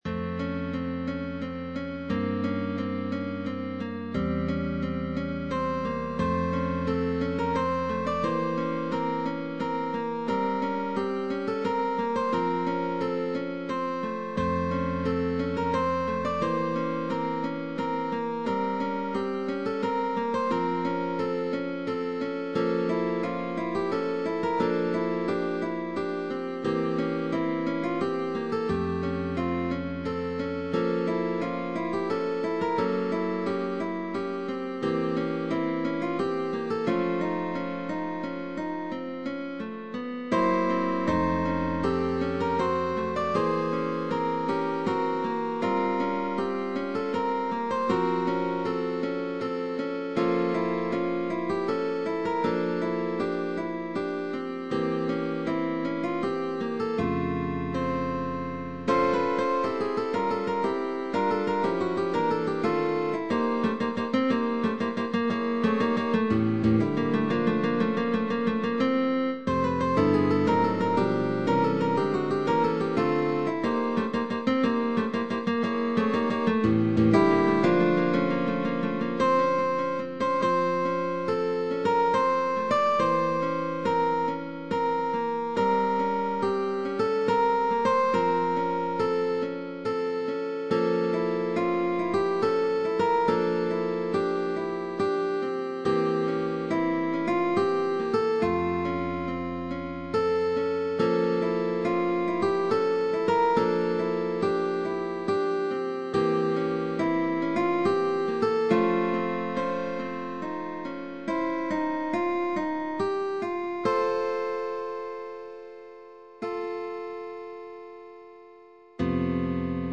GUITAR DUO
Concert duos